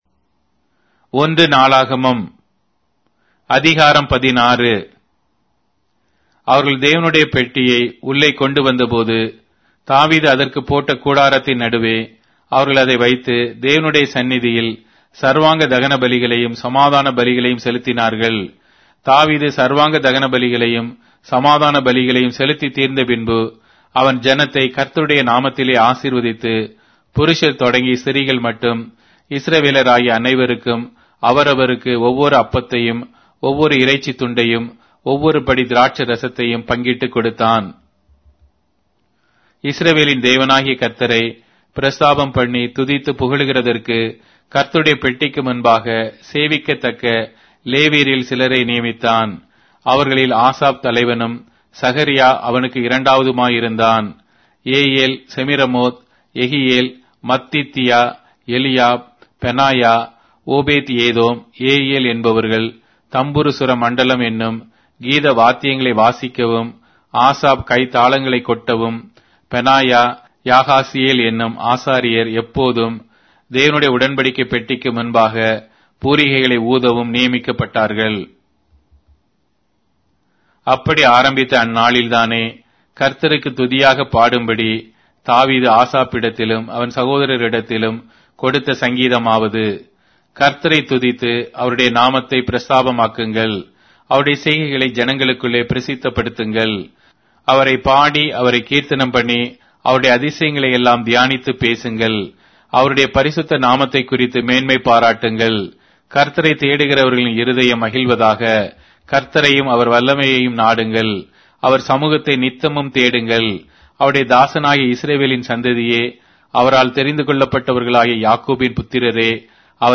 Tamil Audio Bible - 1-Chronicles 9 in Ervbn bible version